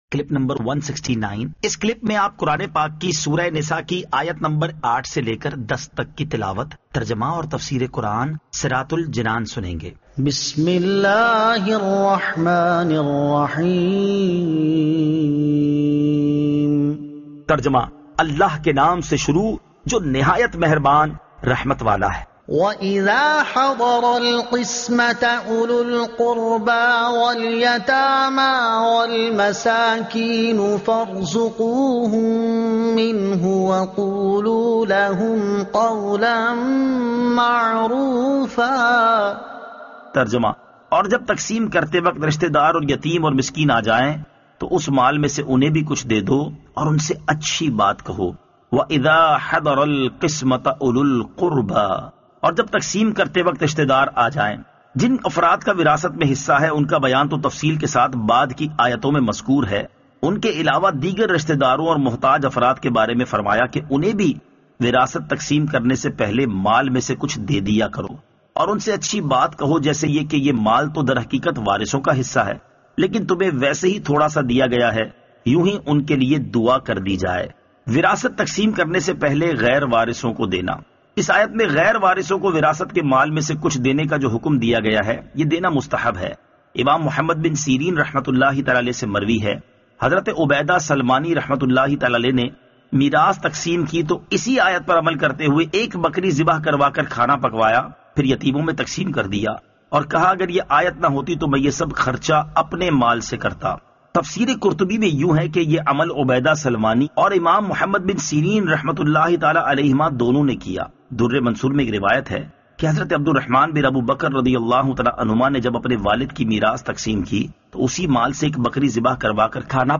Surah An-Nisa Ayat 08 To 10 Tilawat , Tarjuma , Tafseer